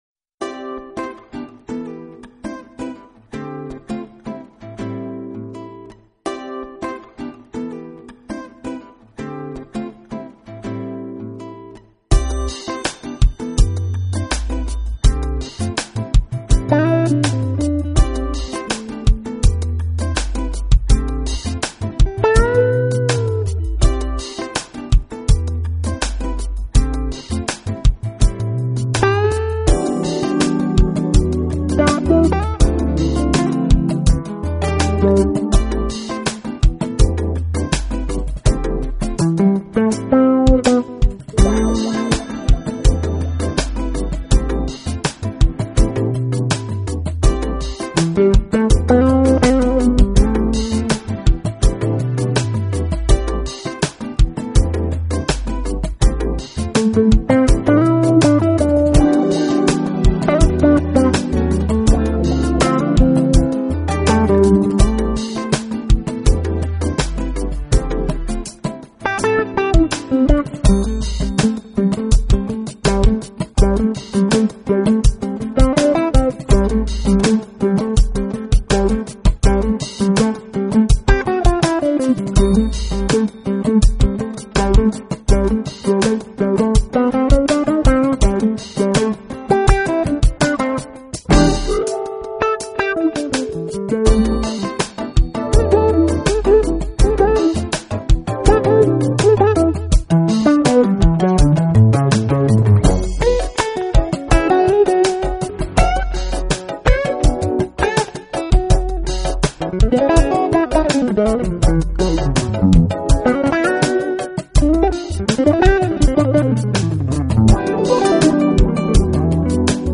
爵士吉他